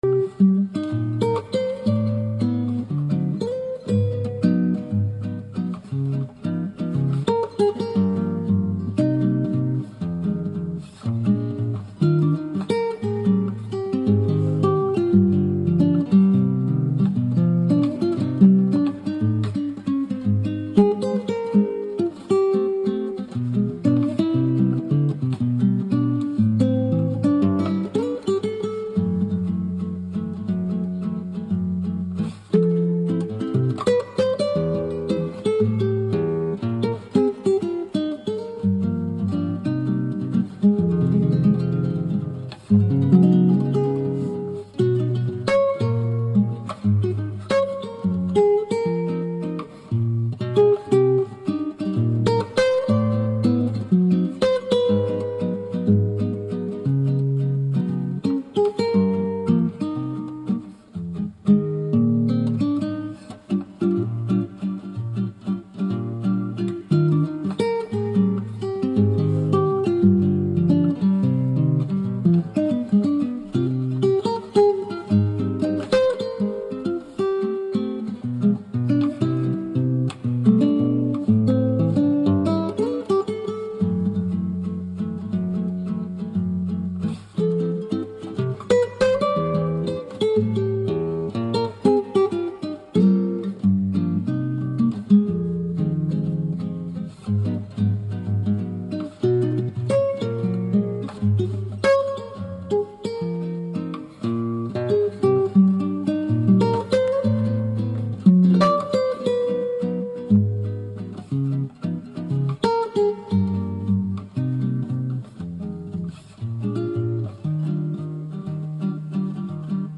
*note – recording begins during 3rd track